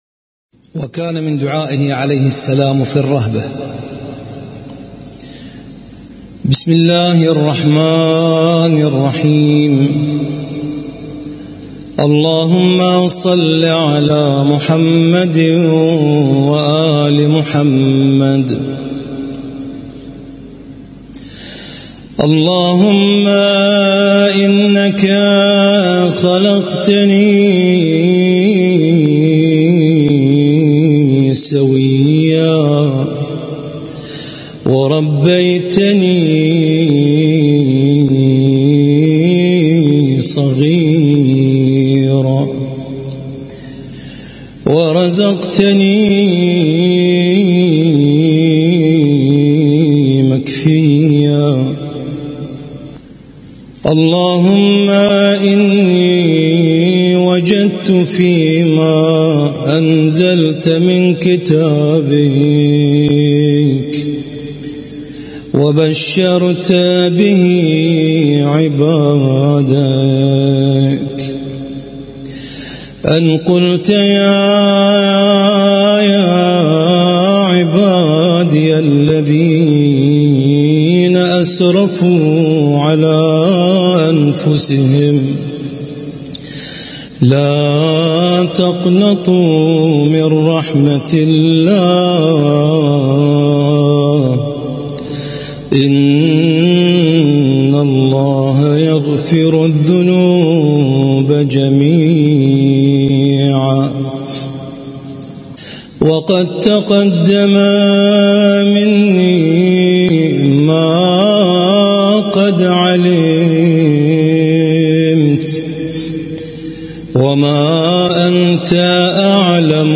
القارئ: القارئ